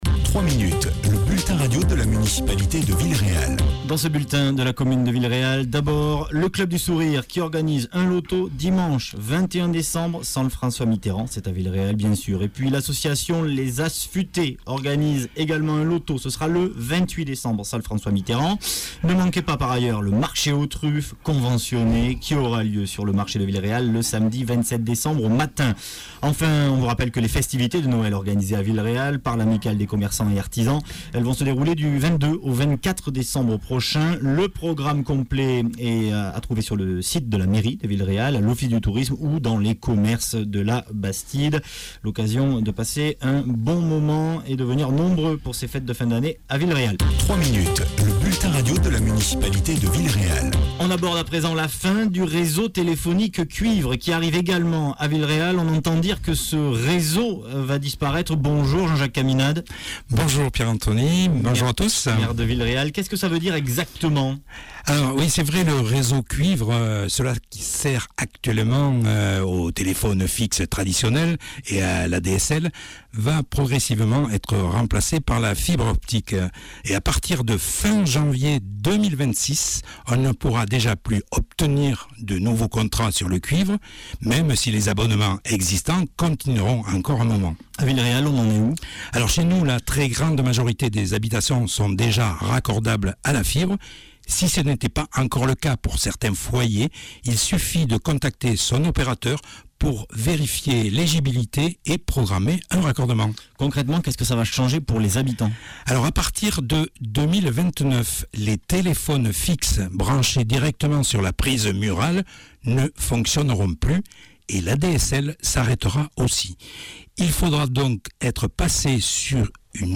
Progressivement, les lignes téléphoniques traditionnelles, les "lignes cuivre" sont amenées à disparaître. Ce sera aussi le cas à Villeréal, comme l’explique le maire Jean-Jacques Caminade.